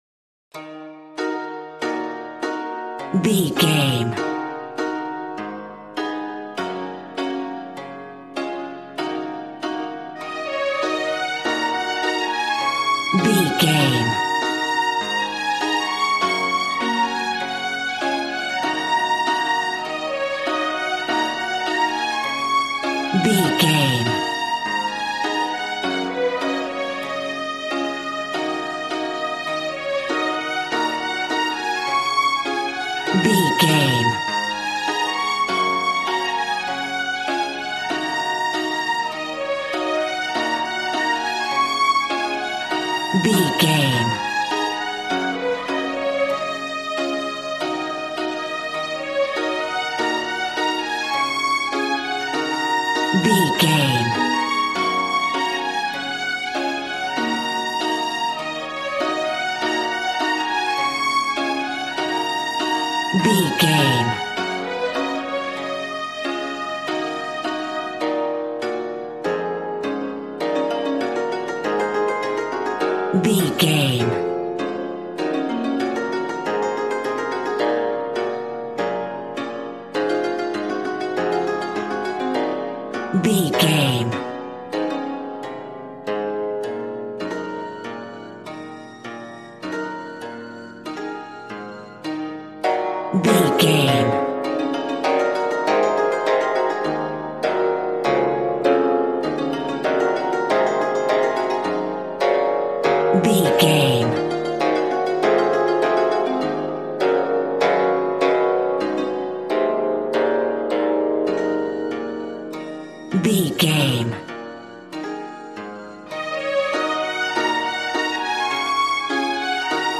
Aeolian/Minor
B♭
smooth
conga
drums